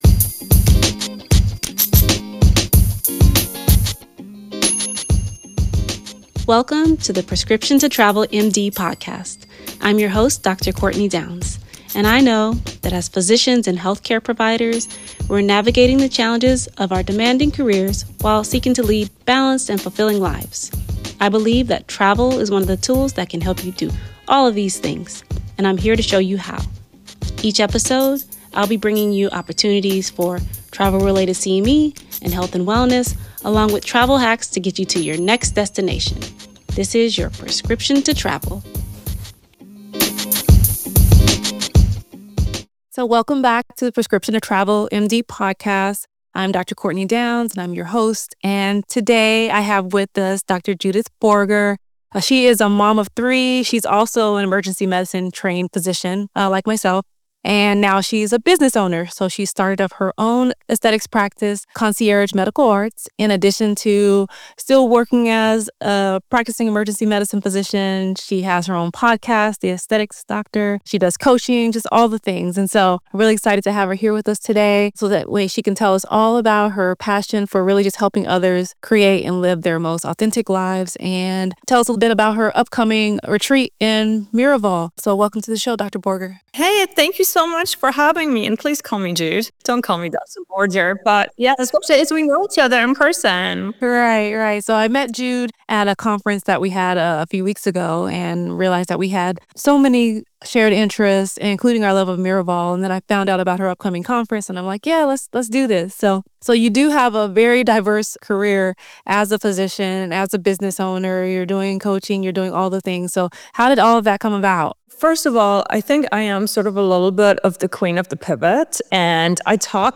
• Clean, Noise free, balanced broadcast-ready sound
• Loudness-optimized audio for all major platforms